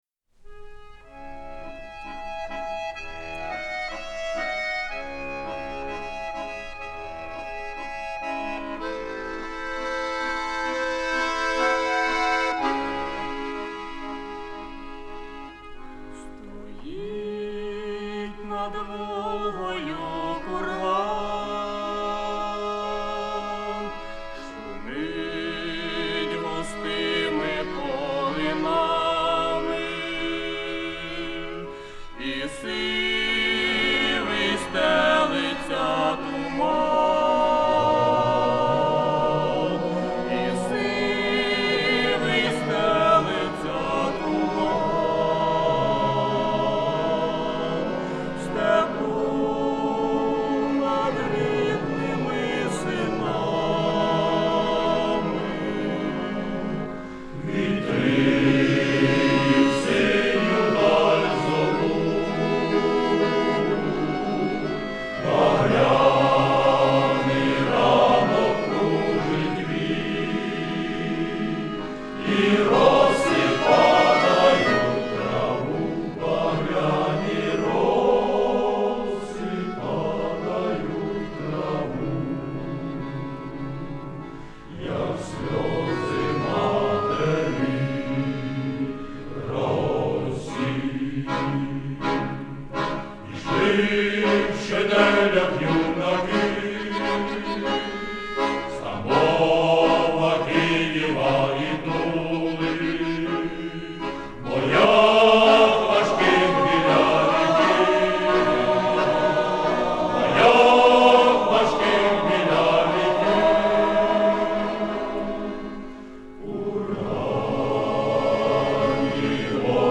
В этом исполнении поётся "как слёзы матери-России"